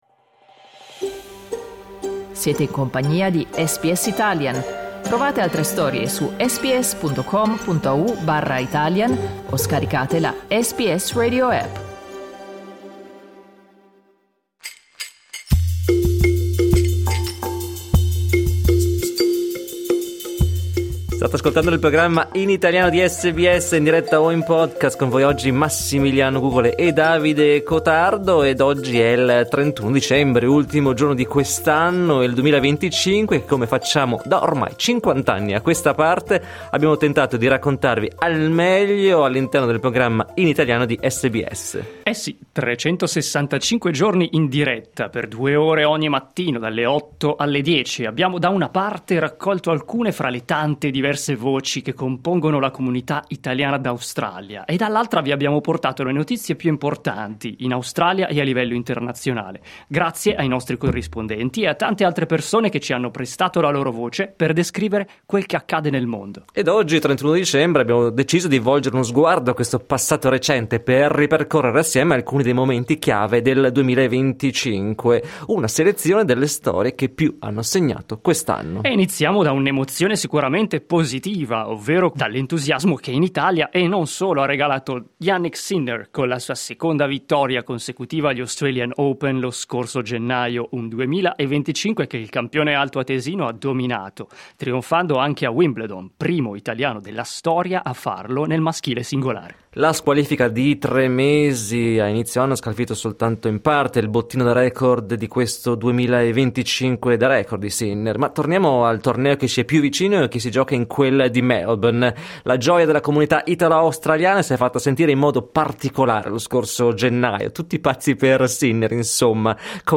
Nelle ultime ore del 2025, ripercorriamo i fatti che più hanno segnato gli ultimi 12 mesi attraverso i nostri corrispondenti e le persone che ci hanno prestato la loro voce per descrivere quel che accade nel mondo.
In questo servizio abbiamo raccolto una selezione di estratti dalle interviste realizzate da SBS Italian durante l'anno, per ripercorrerne i momenti più salienti.